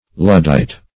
Luddite \Lud"dite\, n.